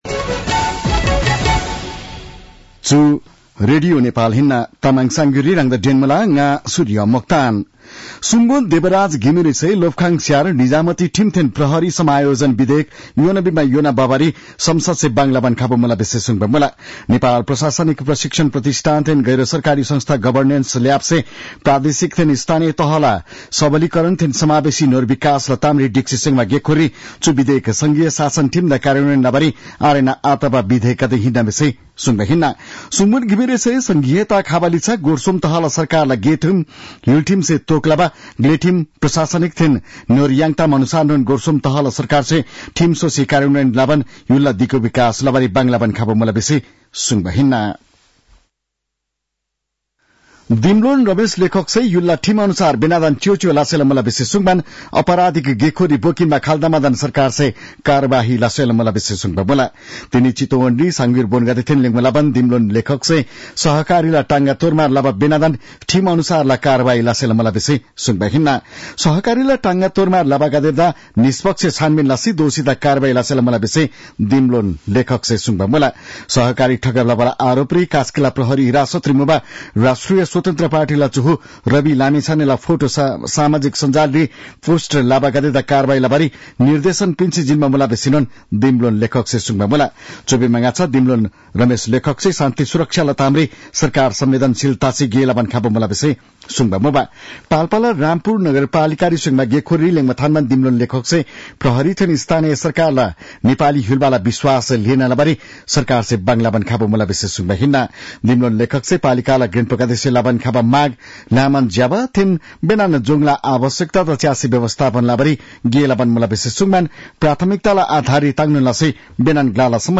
तामाङ भाषाको समाचार : ५ पुष , २०८१
Tamang-news-9-04.mp3